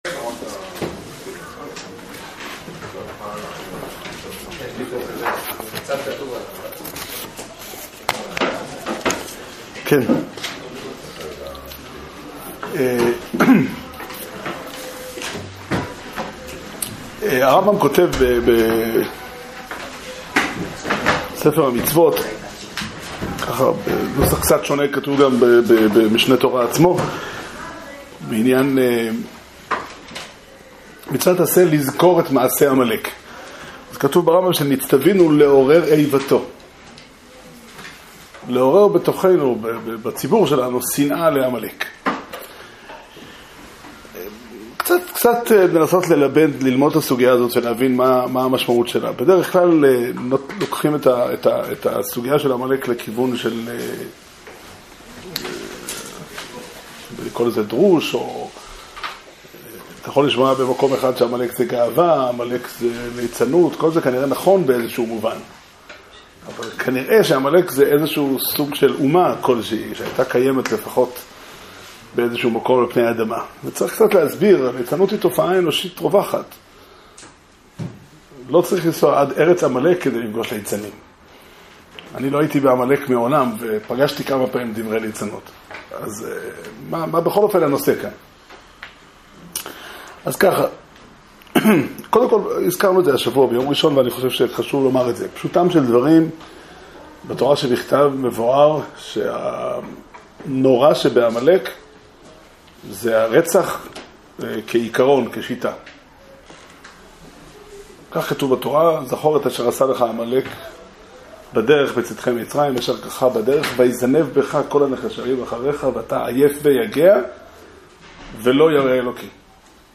שיעור שנמסר בבית המדרש פתחי עולם